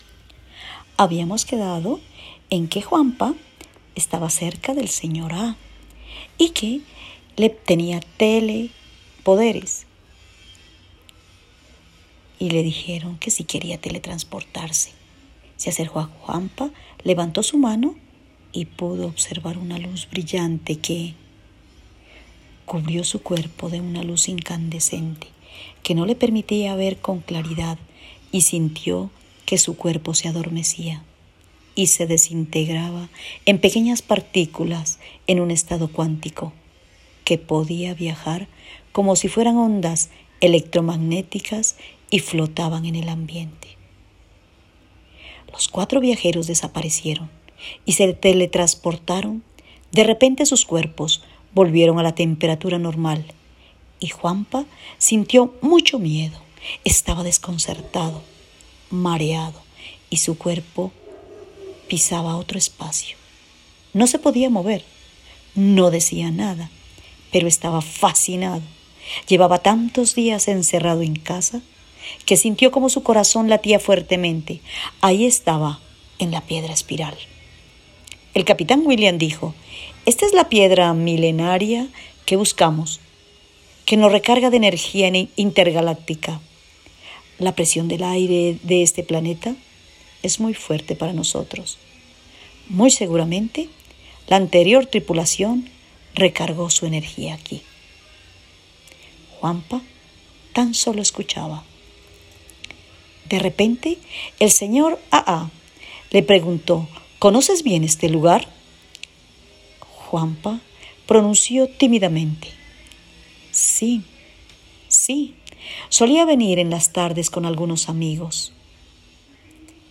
Documentos sonoros (mapas sonoros, audios, canciones, audio libros, entre otros)